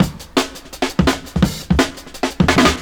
Droppin S 170bpm.wav